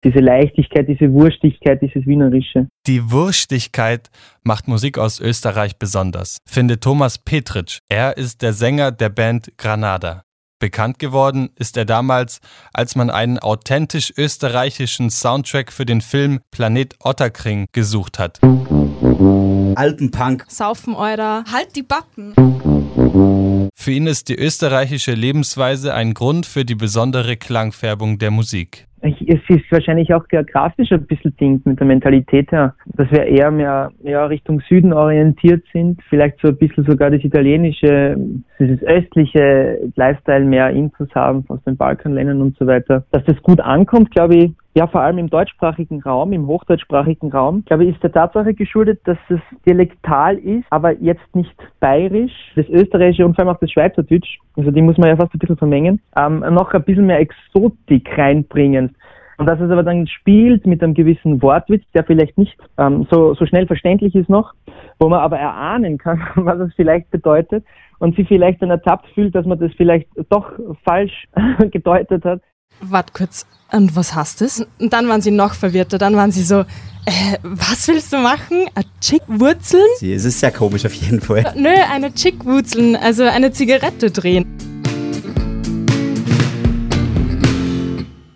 Ein Feature zum neuen Schwung Ösi-Pop